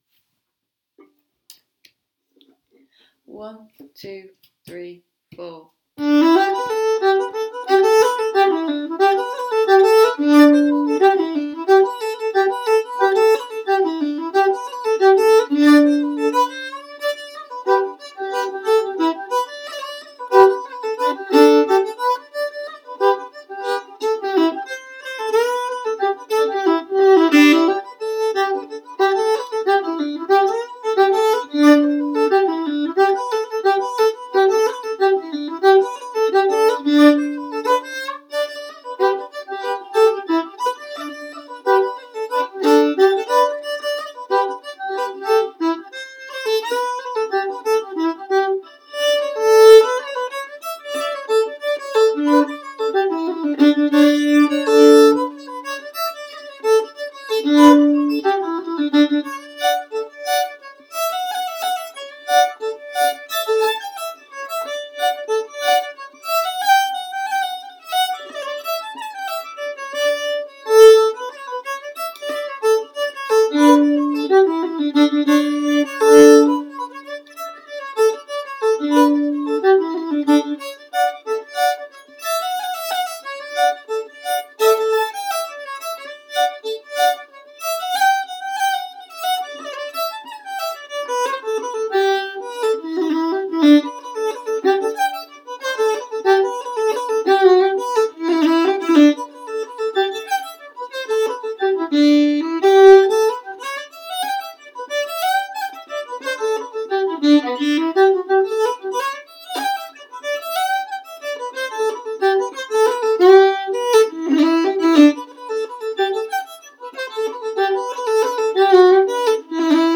Reels